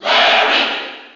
File:Larry Koopa Cheer NTSC SSB4.ogg
Larry_Koopa_Cheer_NTSC_SSB4.ogg.mp3